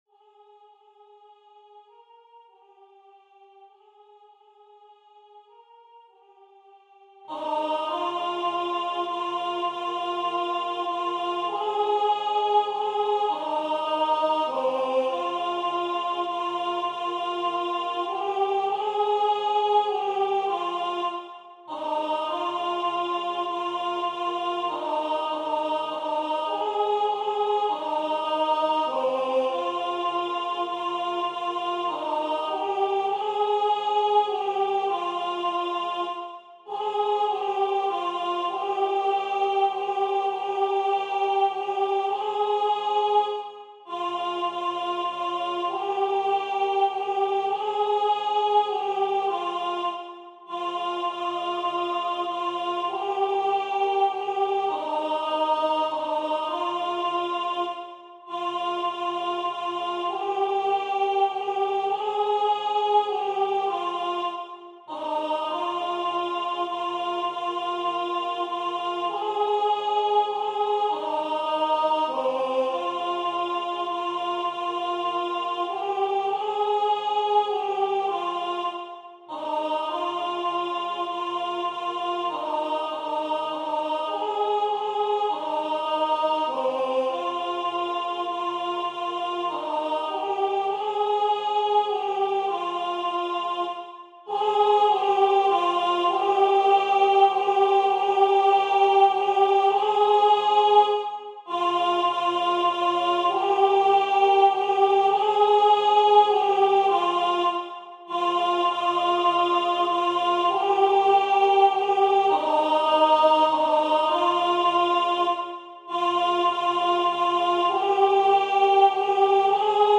- Œuvre pour chœur à 4 voix mixtes (SATB) + 1 voix soliste
Alto Voix Synth